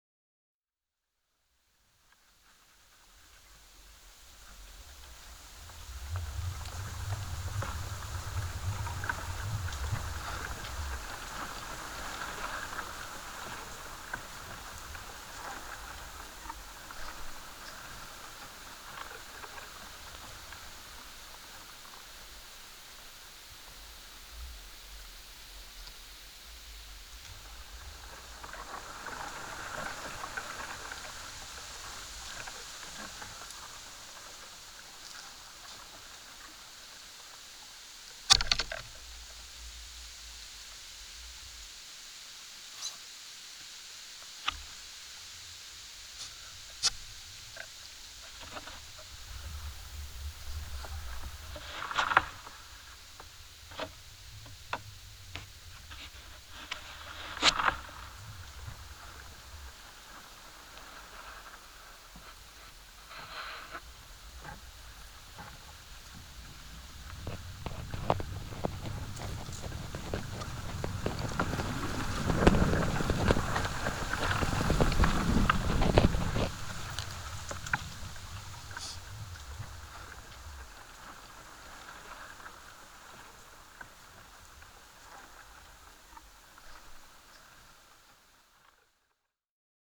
sound art
Performance gestures are restrained, unobtrusive, allowing for the wind and leaves to be heard.
Nature sounds
Jericho Beach (B.C.)